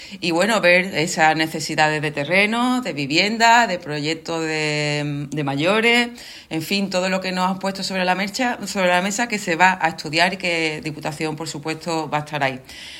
Corte de Almudena Martínez del Junco
estella-presidenta.mp3